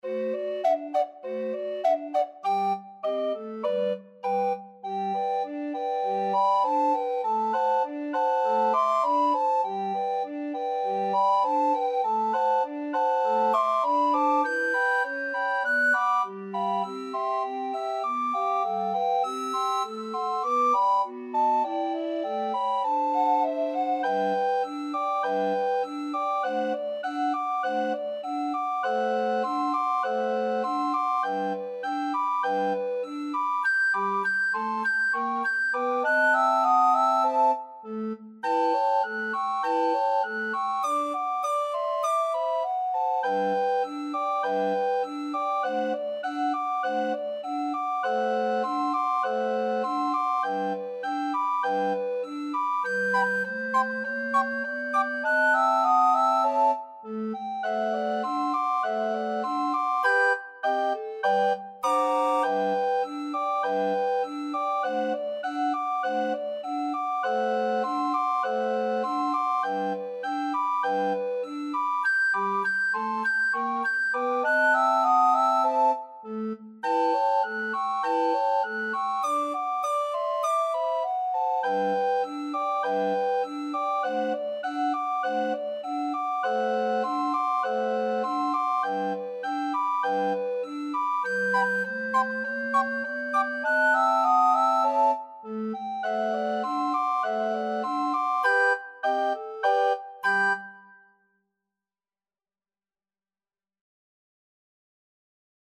Moderato =c.100
2/2 (View more 2/2 Music)
Pop (View more Pop Recorder Ensemble Music)